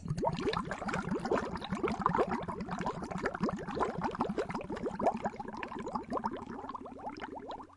水的气泡和潺潺声
描述：少量的冒泡水潺潺流淌，通过Focusrite Scarlett记录在Yeti Blue Pro上。
标签： 汩汩 气泡 气泡 潺潺 咯咯 液态
声道立体声